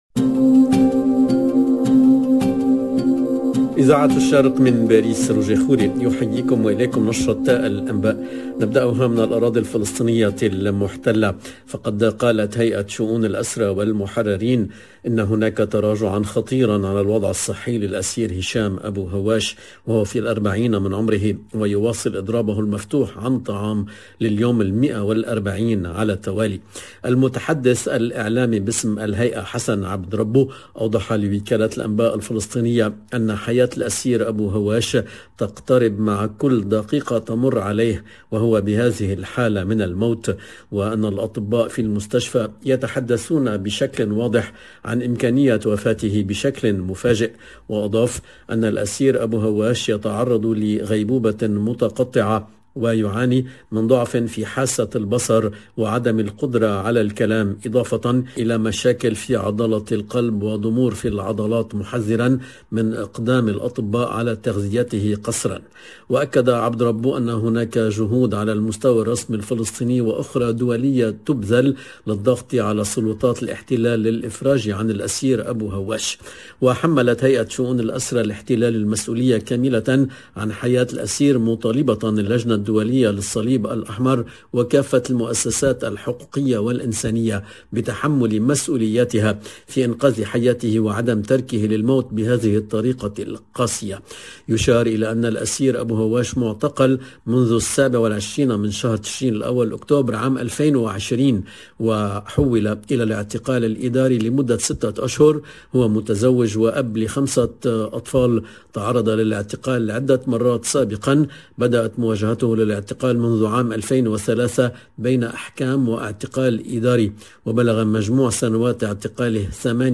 LE JOURNAL DU SOIR EN LANGUE ARABE DU 3/01/22